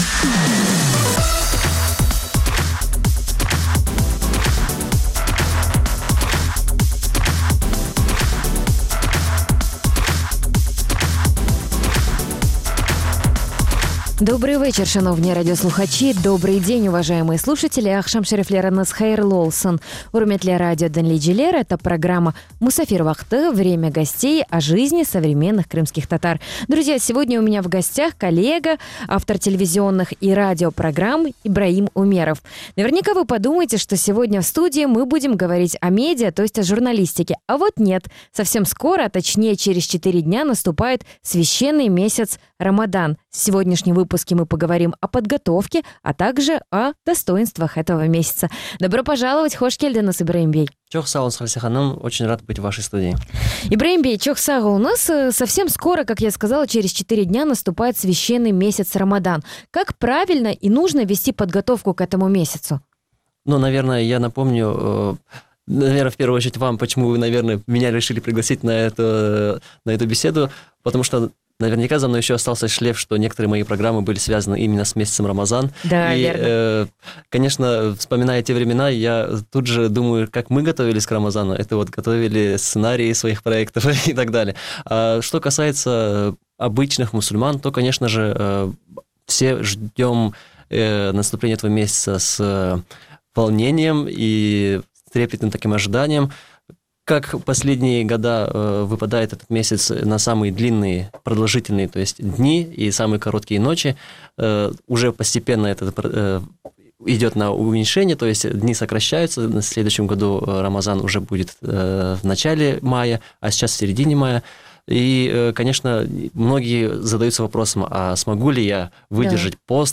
Эфир можно слушать Крыму в эфире Радио Крым.Реалии (105.9 FM), а также на сайте Крым.Реалии